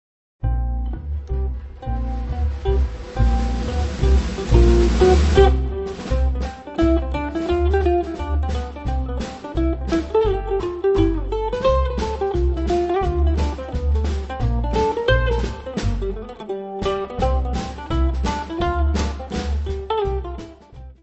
: stereo; 12 cm
Music Category/Genre:  Jazz / Blues